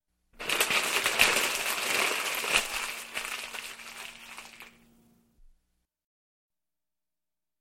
Газета звуки скачать, слушать онлайн ✔в хорошем качестве